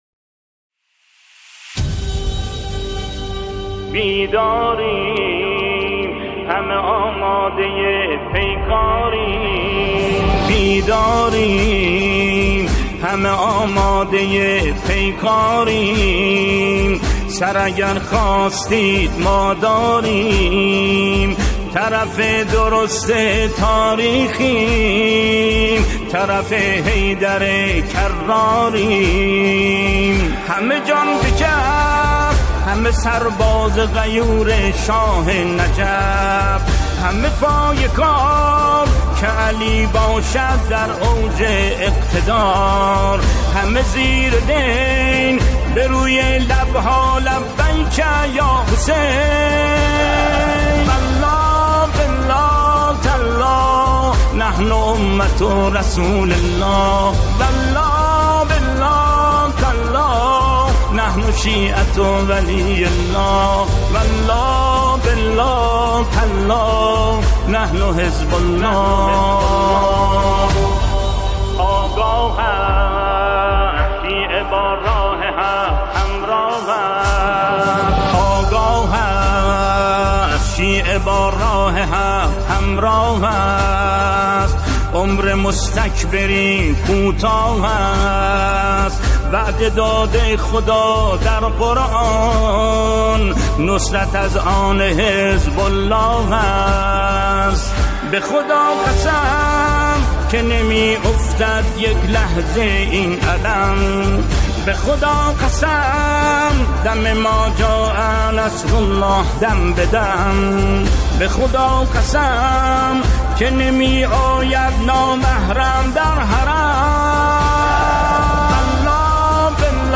نماهنگ زیبای حماسی
نماهنگ مقاومت